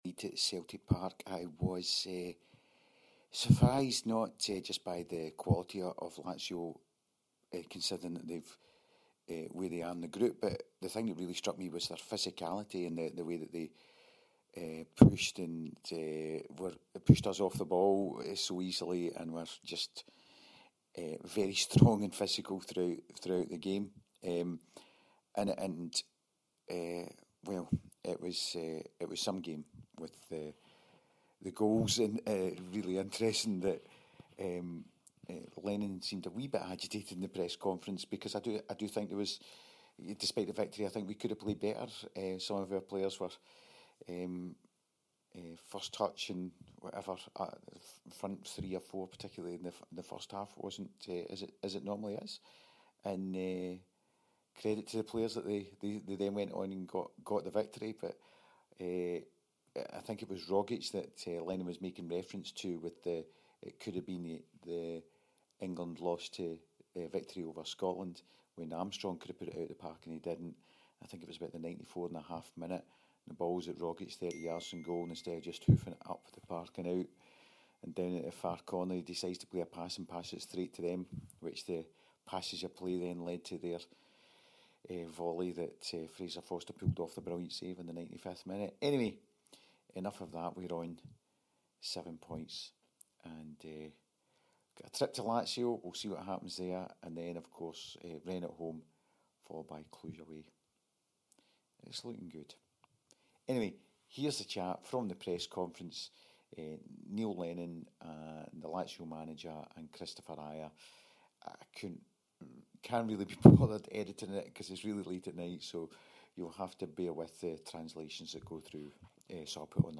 Headliner Embed Embed code See more options Share Facebook X Subscribe The post game reaction following the 2-1 win over the Italians Tonight saw Celtic secure a hard won victory over an excellent Lazio team to move top of the Europea League Group at the half way point. After the game we got the views of Neil Lennon, the Lazio manager and Kris Ajer.